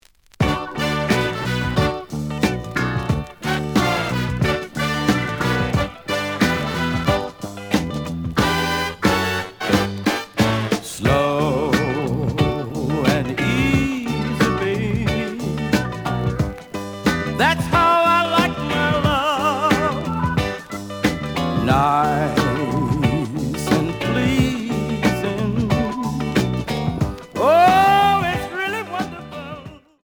The audio sample is recorded from the actual item.
●Format: 7 inch
●Genre: Blues